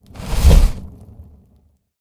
meleeattack-swoosh-magicaleffect-group01-fire-00.ogg